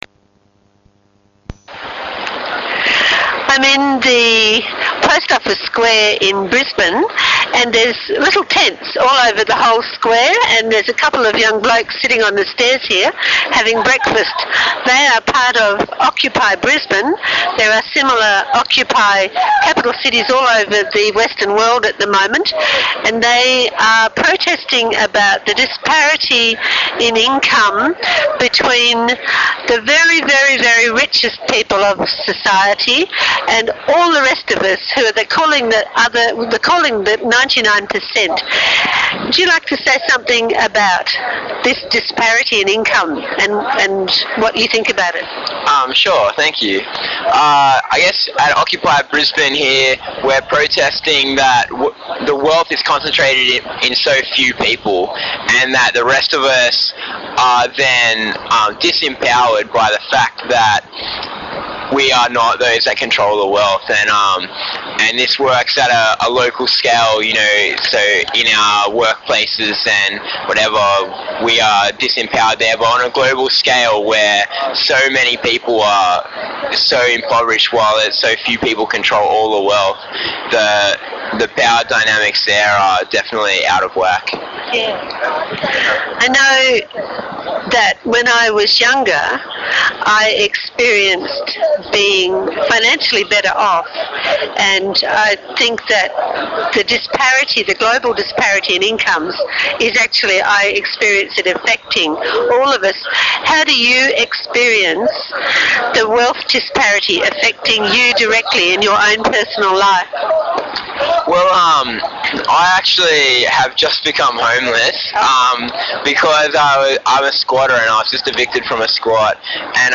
Interview-Occupy-PO-Square.mp3